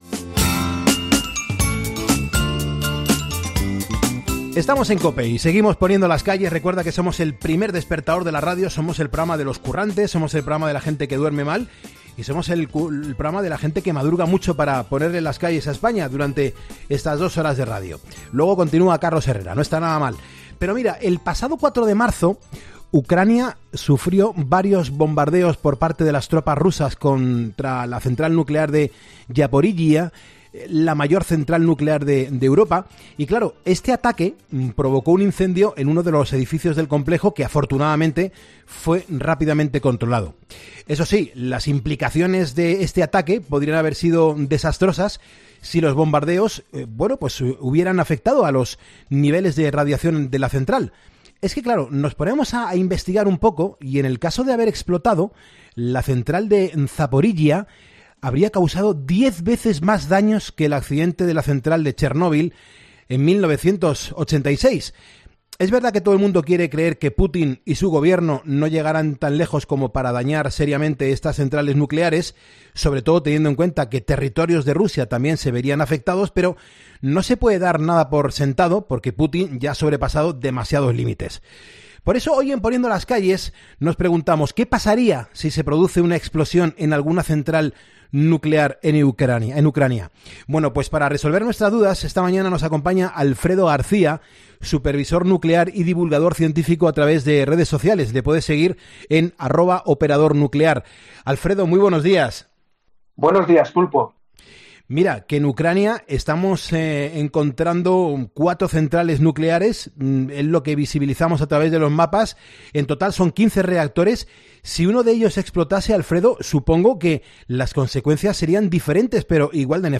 Escucha la entrevista completa para comprender más acerca de cómo funciona una central nuclear y por qué a Rusia no le interesaría destruirlas, si no todo lo contrario: hacerse con el control de cada una de ellas.